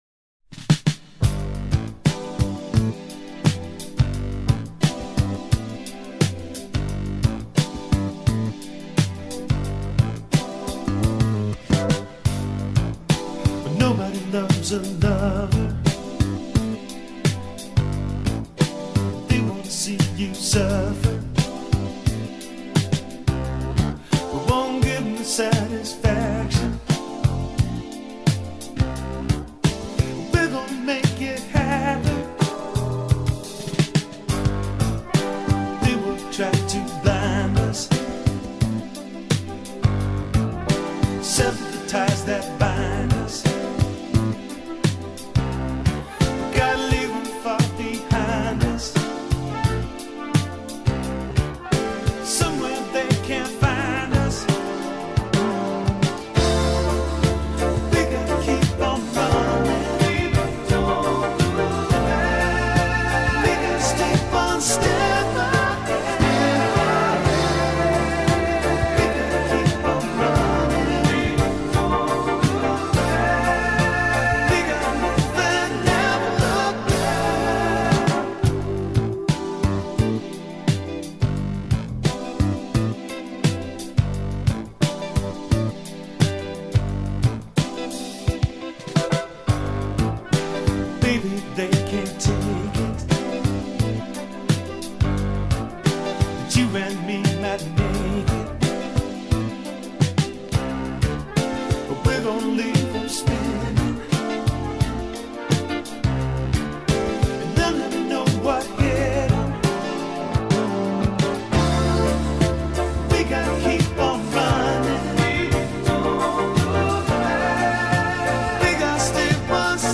early 80’s sophisticated pop
this record is considered a blue-eyed soul masterpiece